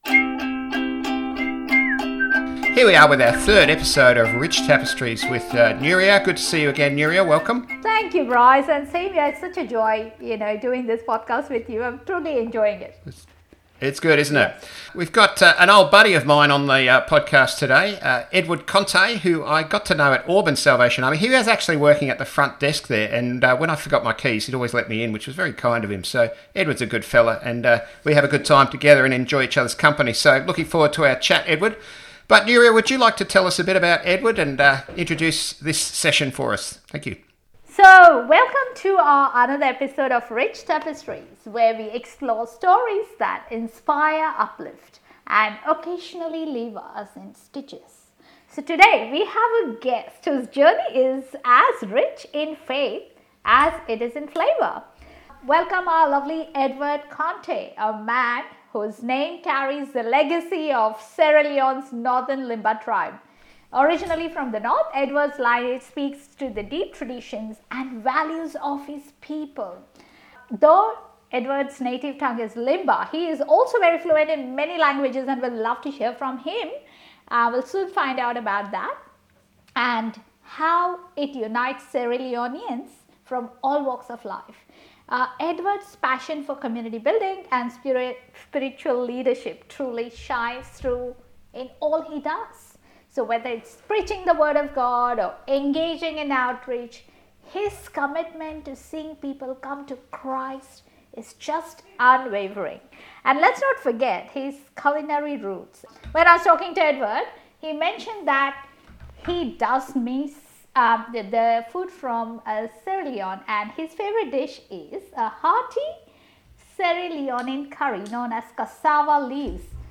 In this Rich Tapestries interview